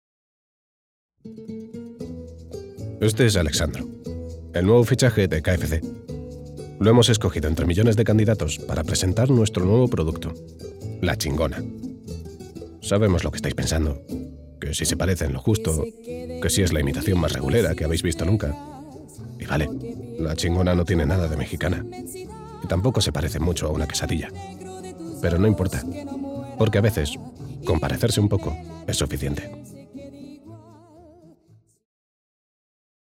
Announcements
Baritone